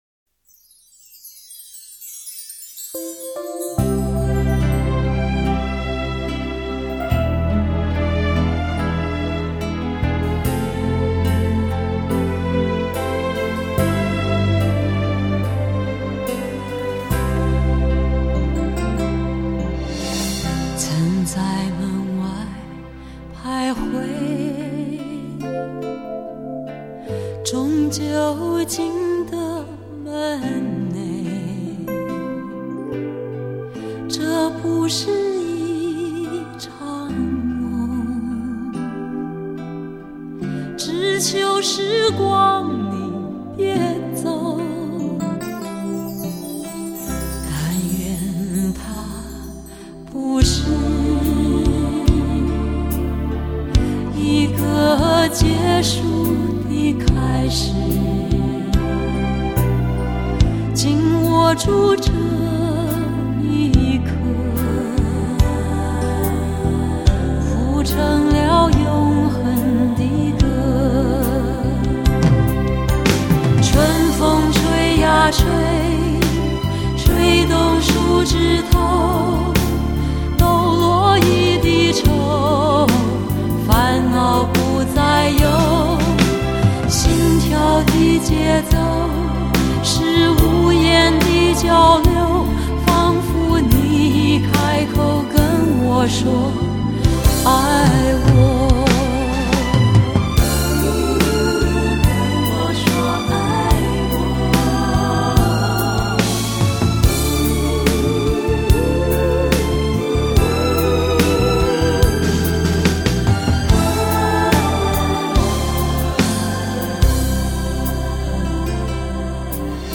从清新隽永的旋律中 唤起心中沉睡的记忆